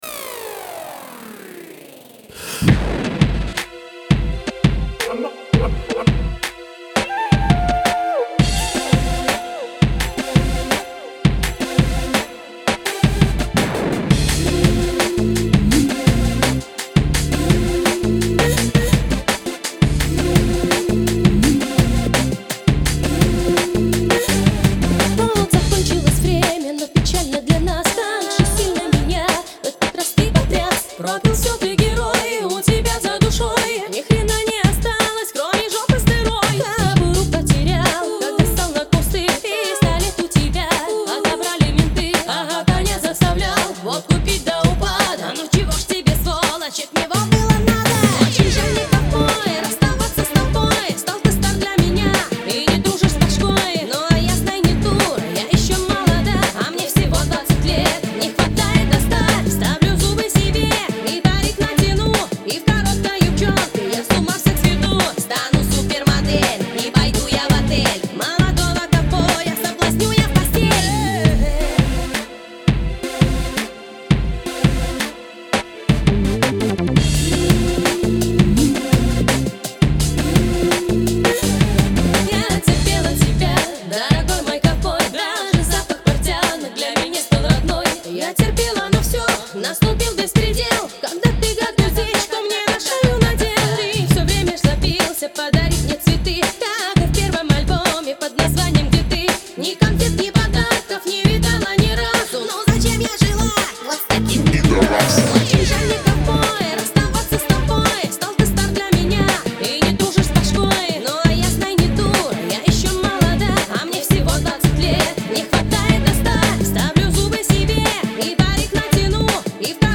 Поп Рок